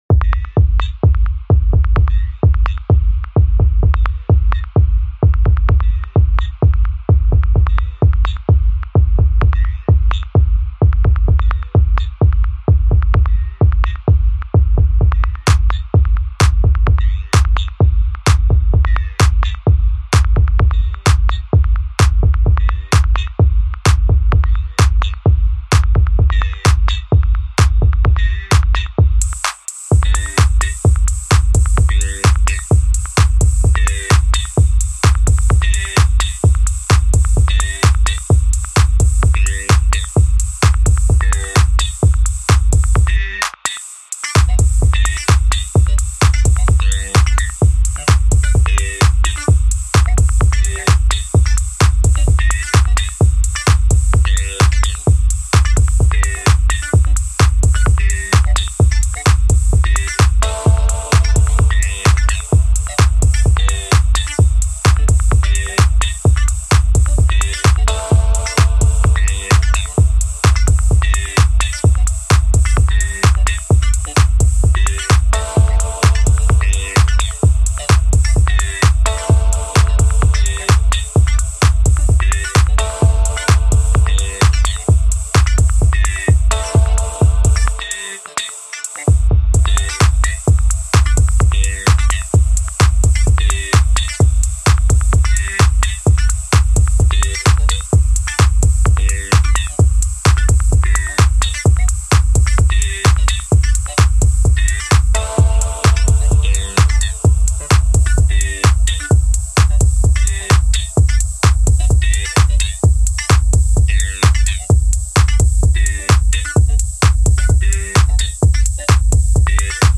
描述：舞蹈和电子音乐|放克
Tag: 合成器